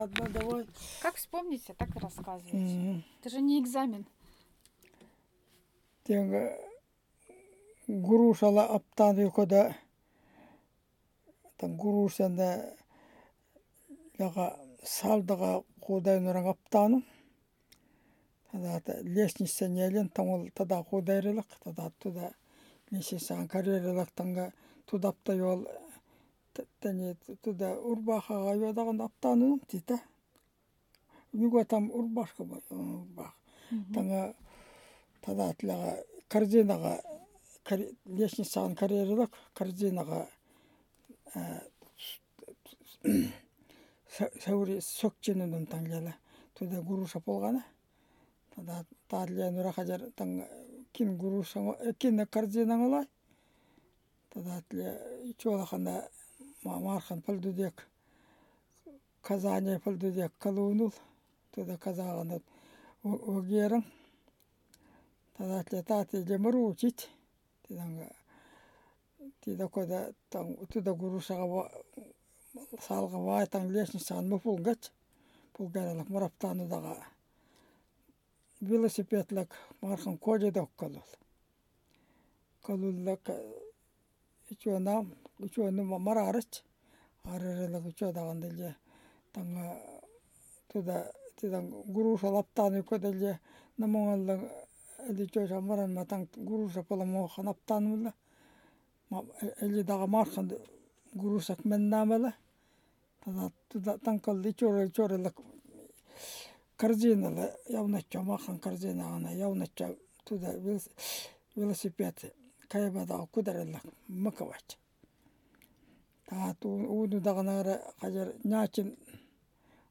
This session was recorded in Yakutsk (Russian Federation) in 2022